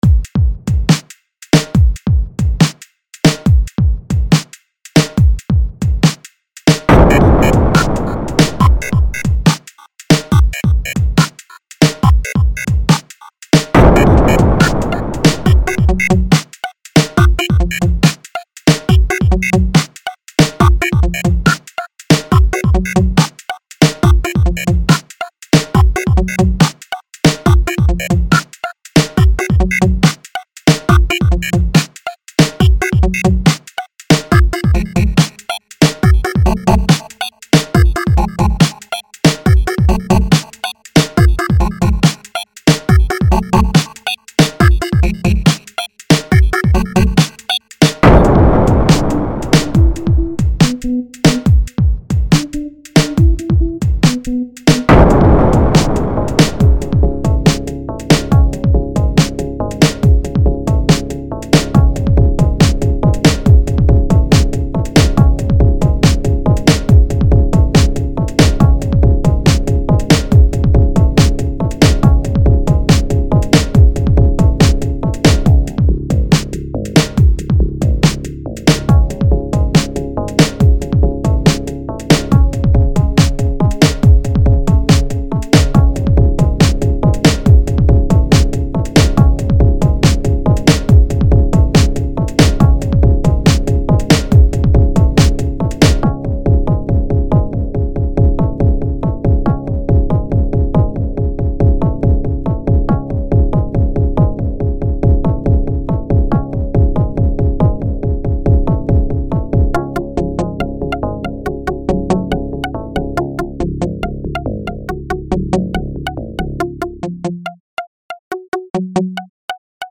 It has sufficient variation, both in the theme and the rhythm, and always kept surprising me with its little twists. Towards the end, it sounded as if it was going to drown in a monotonous repeating of the low theme, but even there it surprised me and went back to the high theme before ending.